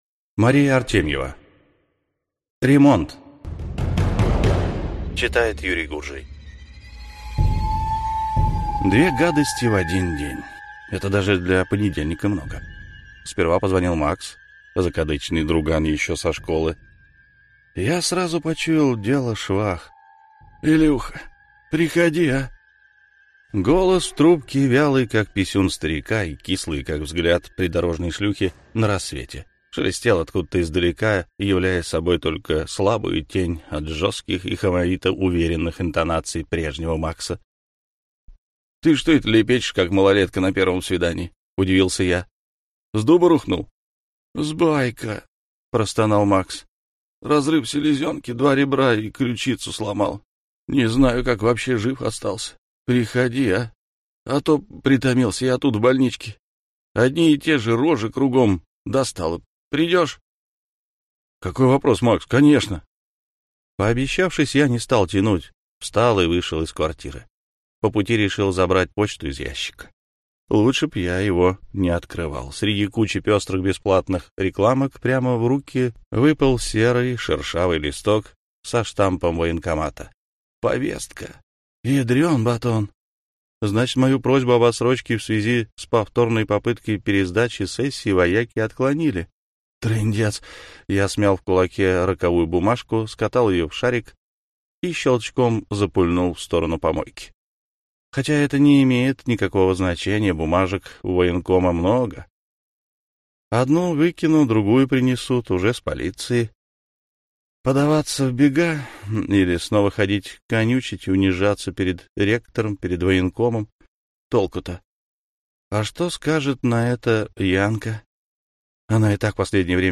Аудиокнига Письма незнакомцев | Библиотека аудиокниг